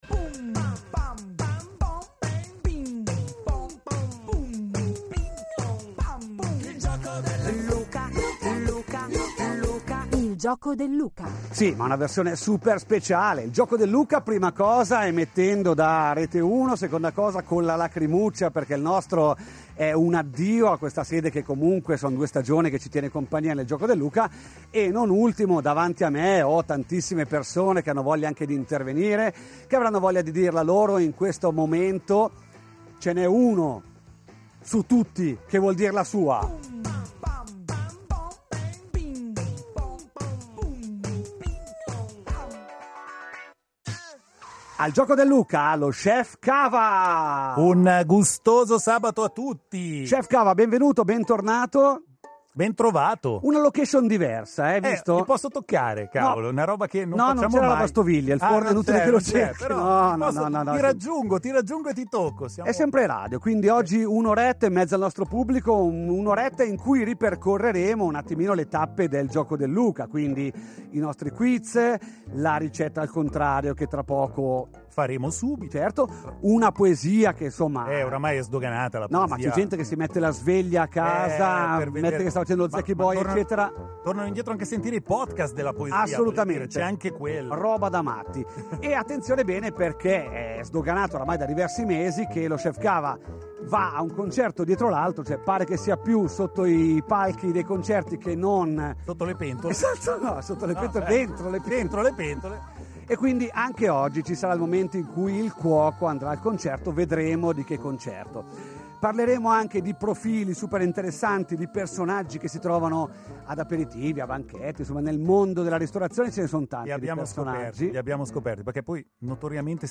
Dalle 15 alle 16: puntata speciale in occasione delle porte aperte e soprattutto del nostro addio alla sede radio di Besso.